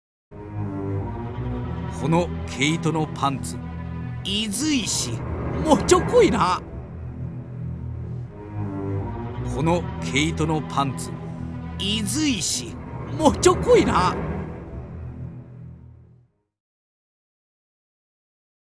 北海道弁を読み上げる“新感覚かるた”！
BGM・効果音付きで楽しさ倍増